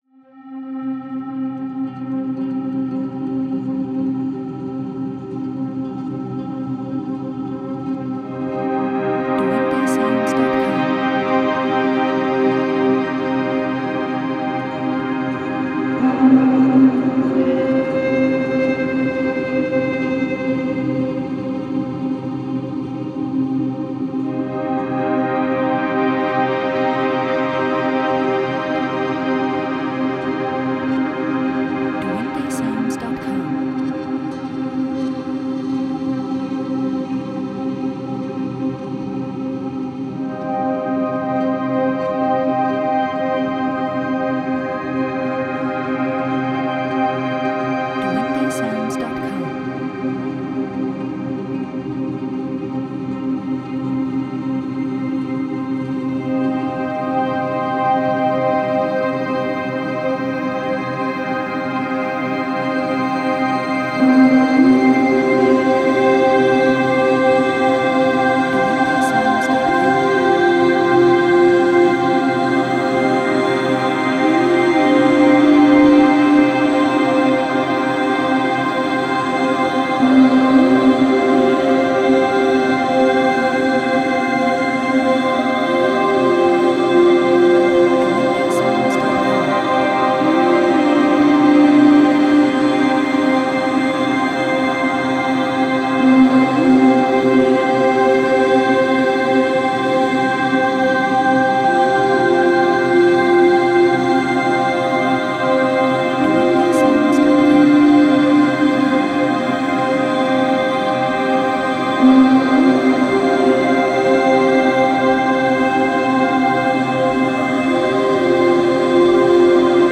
Positive;Sad;Thoughtful
Cinematic;Ambient